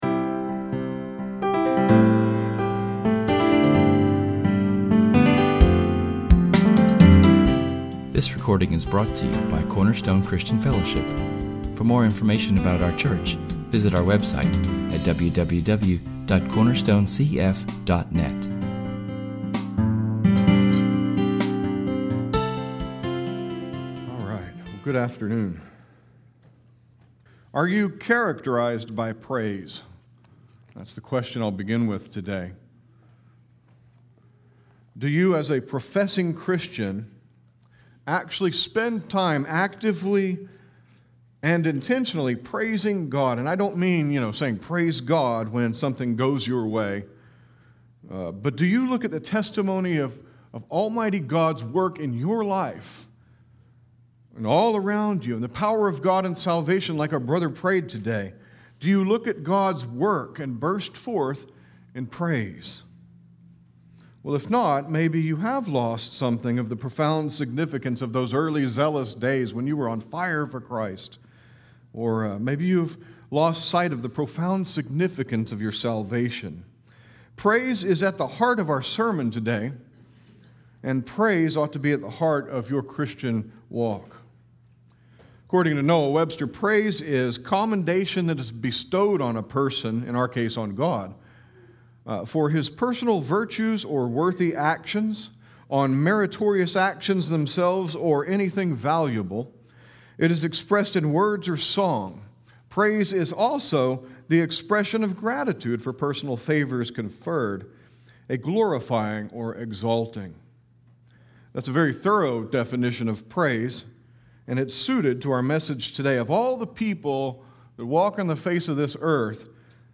This week’s sermon is entitled “David’s Song,” and is from [esvignore]2 Samuel 22[/esvignore].